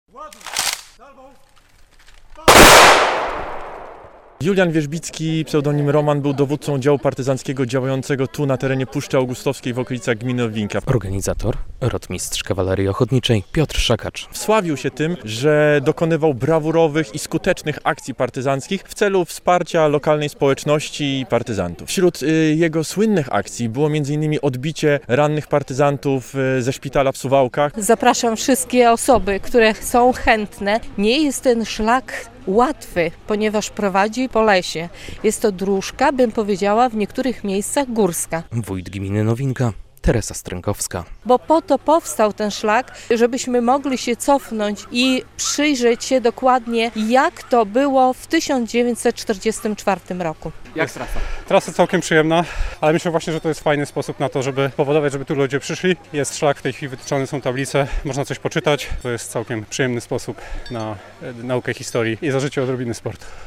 W wydarzeniu uczestniczyli okoliczni mieszkańcy, wojskowi oraz przedstawiciele samorządów, którzy złożyli kwiaty w miejscu śmierci Juliana Wierzbickiego.